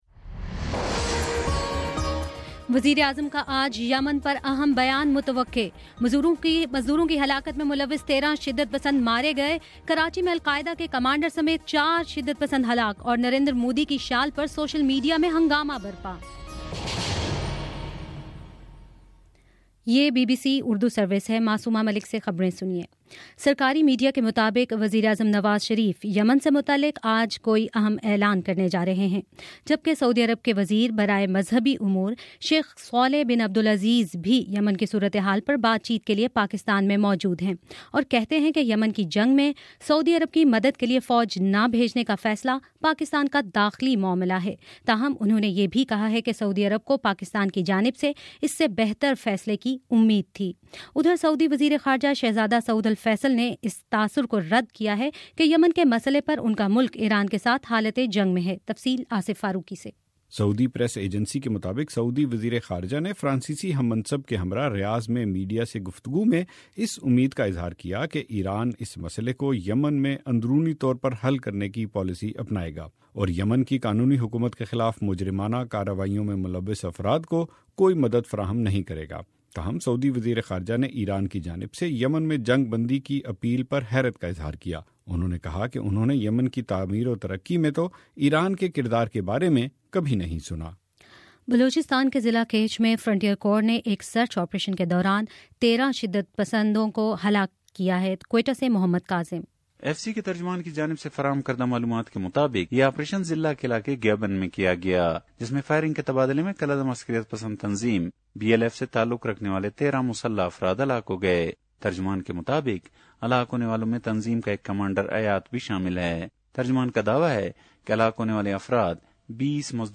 اپریل13 : شام پانچ بجے کا نیوز بُلیٹن